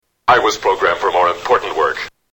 Category: Movies   Right: Personal
Tags: Lost in Space Robot Sounds Robot Sounds Lost in Space Robot clips Robot Lost in Space